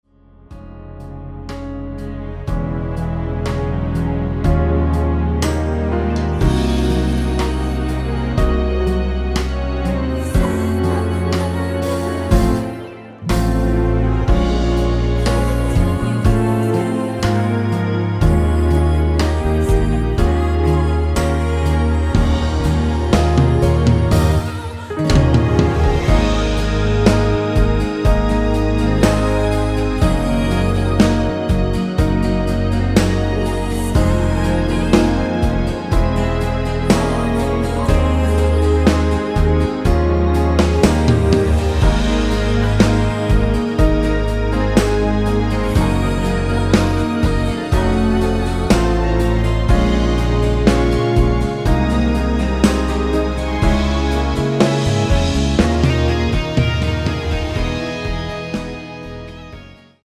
코러스 포함된 MR 입니다.
Ab
앞부분30초, 뒷부분30초씩 편집해서 올려 드리고 있습니다.